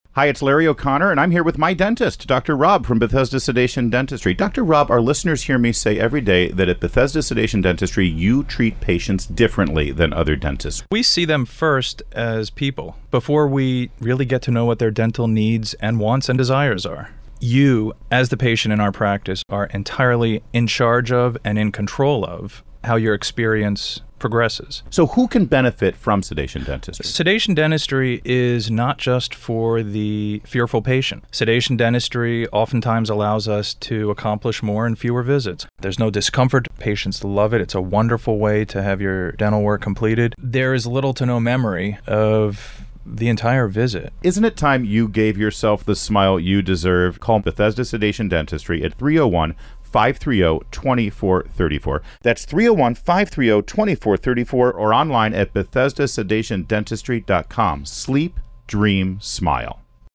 Larry O’Connor, Radio Personality
Audio Testimonials